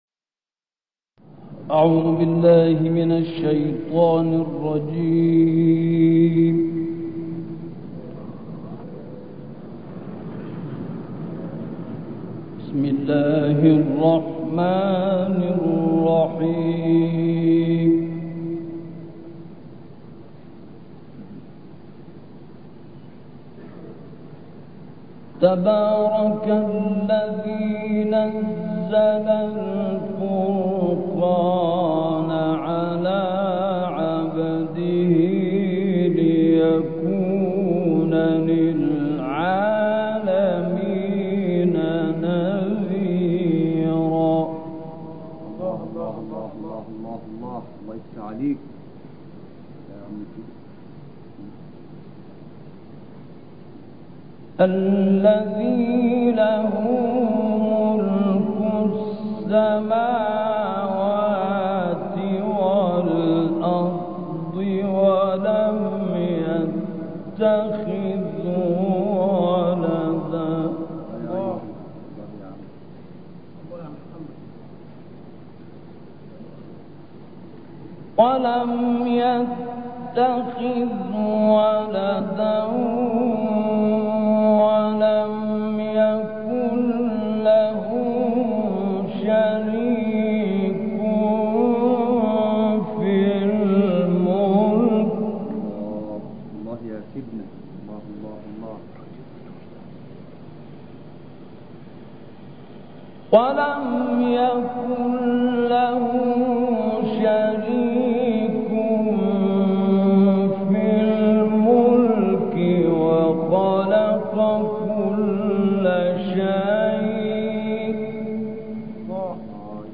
فرقان با تلاوت محمد احمد شبیب +دانلود/ بیم دادن از مجازات‌هاى دوزخ
گروه فعالیت‌های قرآنی: قطعه‌ای از تلاوت شیخ محمد احمد شبیب از آیات ۱ تا ۳۰ سوره فرقان ارائه می‌شود.